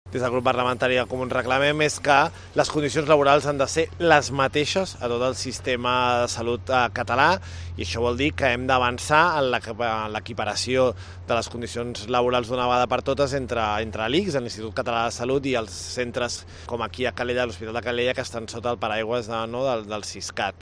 Els Comuns reclamen a la consellera de Salut, Olga Pané, que acabi amb les desigualtats laborals i salarials que hi ha entre els treballadors del sistema de salut català. El portaveu parlamentari, David Cid, s’hi ha manifestat a les portes de l’Hospital Sant Jaume, que és un centre concertat que pertany al SISCAT, després d’haver-se reunit amb el comitè d’empresa per escoltar les seves demandes.